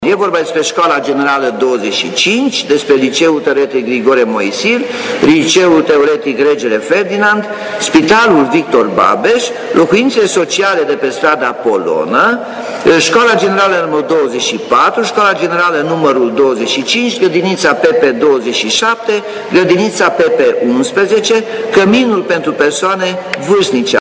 Potrivit primarului Nicolae Robu, cererile de finanțare vor fi depuse de îndată ce acest lucru va fi posibil: